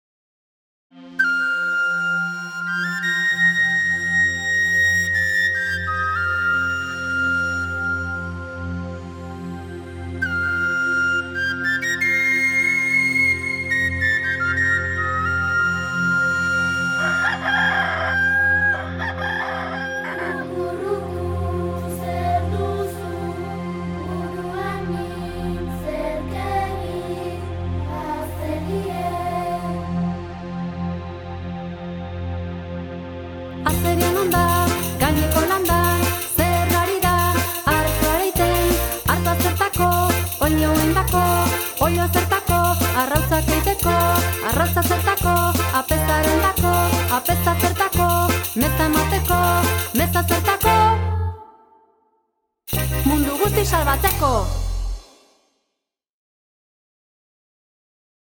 Mota: haur joko-kanta.
Azpimota: elkarrizketa kateatua
CDaren 19. entzungaia (moldatua). 0'58''